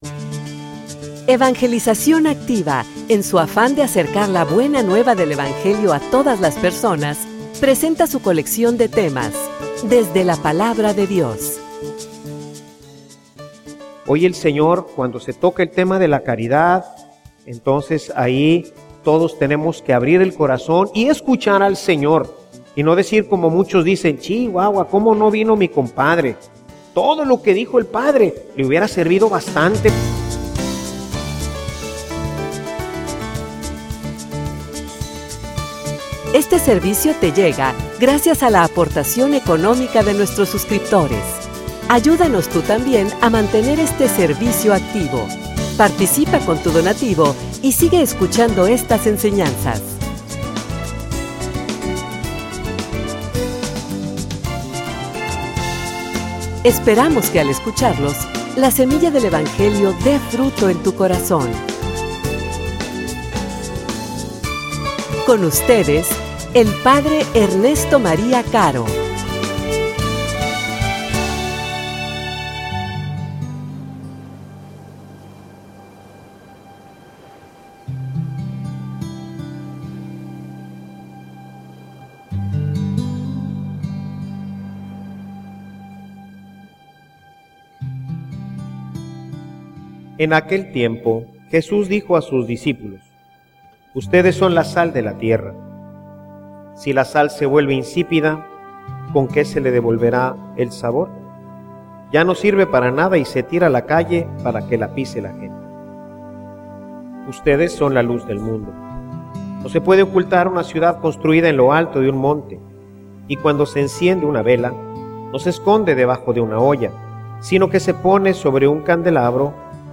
homilia_Caridad_que_ilumina.mp3